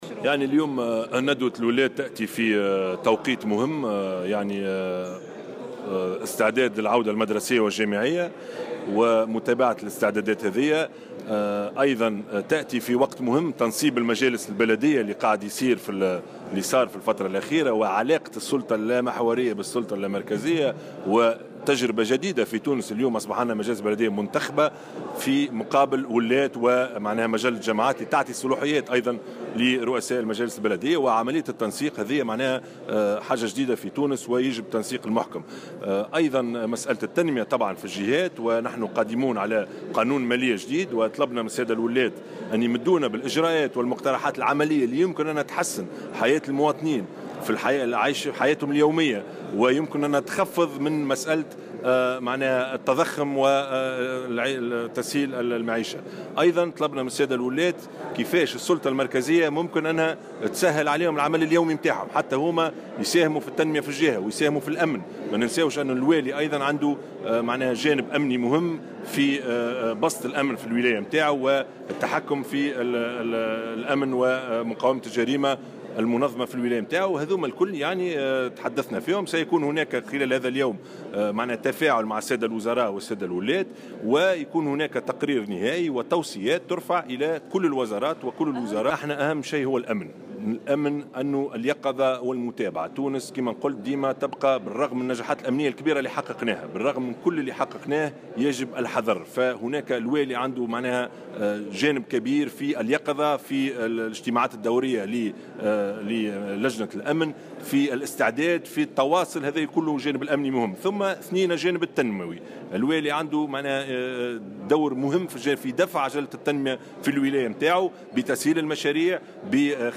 أكد رئيس الحكومة، يوسف الشاهد اليوم، خلال افتتاح اشغال الندوة الدورية للولاة بثكنة الحرس الوطني بالعوينة، على أهمية العمل الميداني و ضرورة تواصل الولاة مع المواطنين.